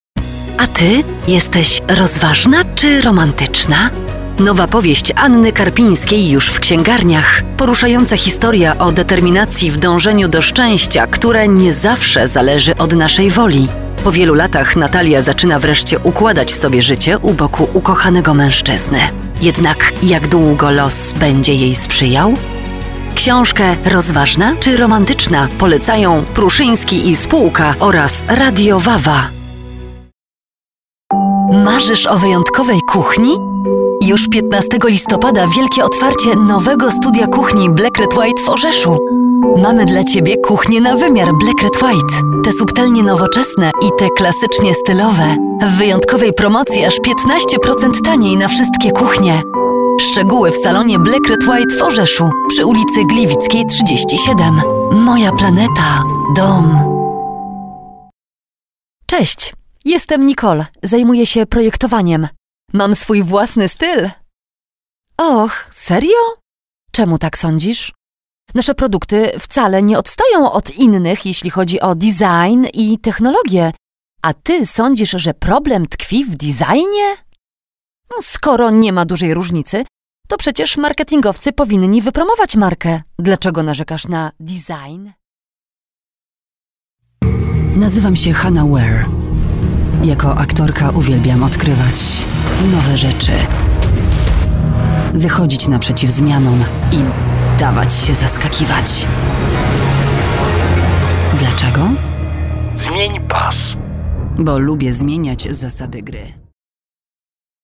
Weiblich